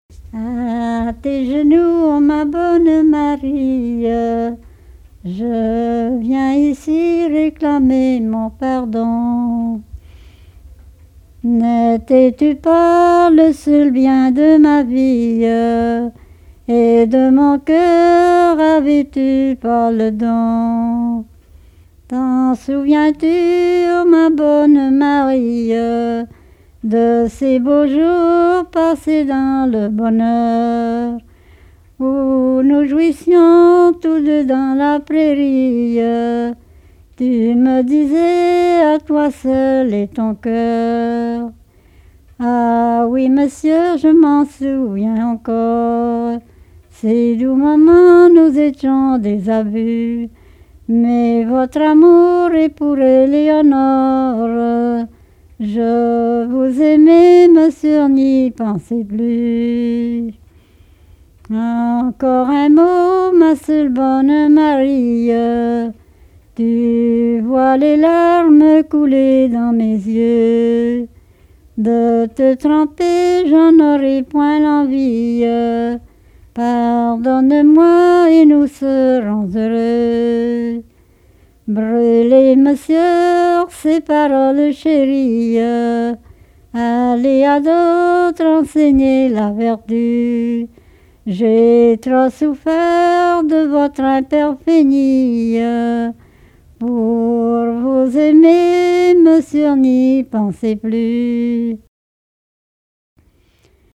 Genre dialogue
Répertoire de chansons populaires et traditionnelles
Pièce musicale inédite